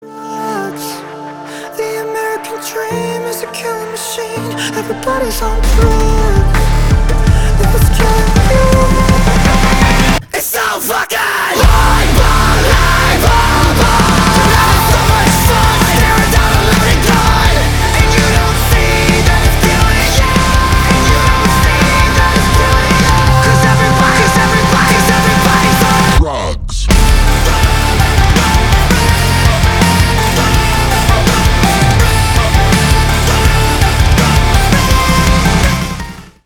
Kategória: Rock
Minőség: 320 kbps 44.1 kHz Stereo